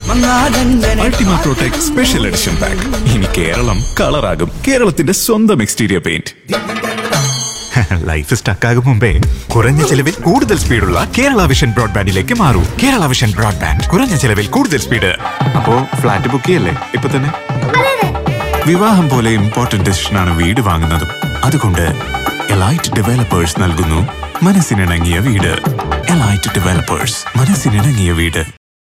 Male
Indian Accent /Neutral English voice with a warm, clear, and versatile tone.
Television Spots
Malayalam Voice Over Reel